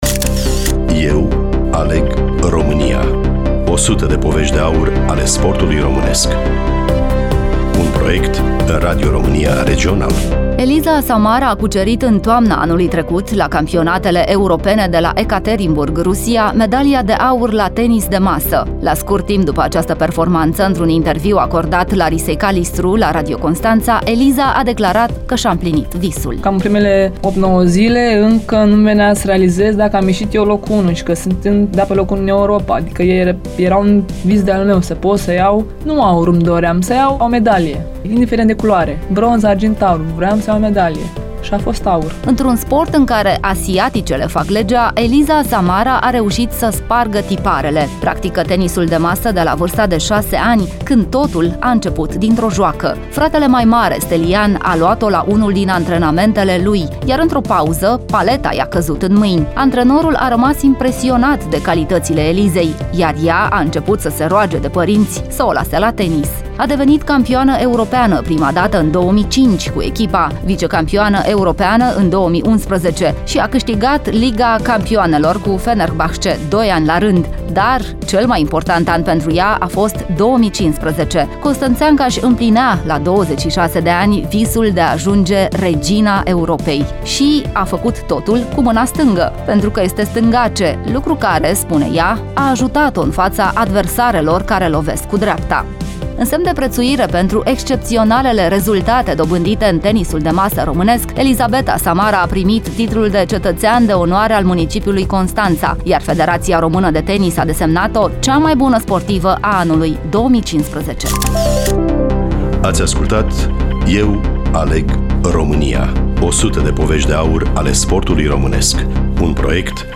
Studioul Radio Romania Constanţa
Voice over